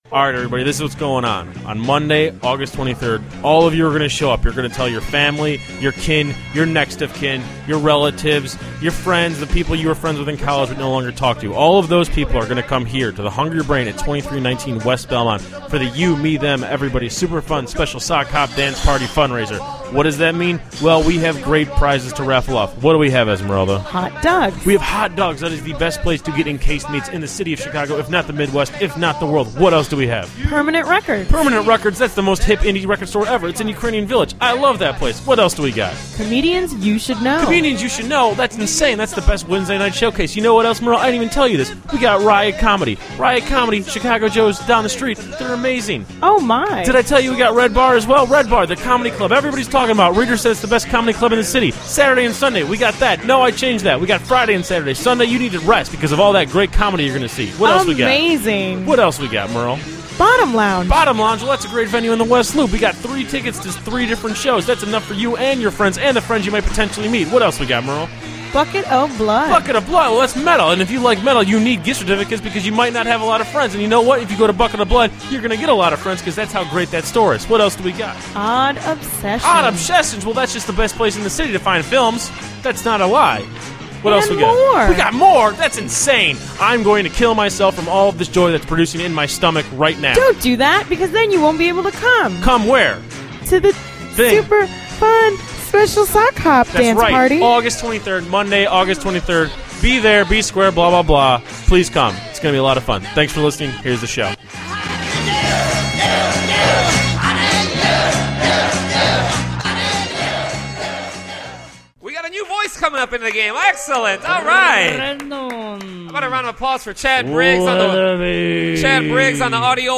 Chicago Cubs @ San Francisco Giants live call at the Hungry Brain